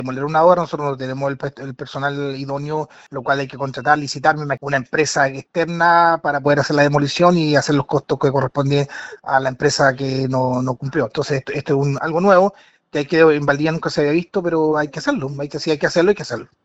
Para el concejal independiente ligado a Renovación Nacional, Marcos Santana, esta demora en el accionar podría tratarse a temas de costos, afirmando que si la determinación municipal fue demoler, esta se debe cumplir.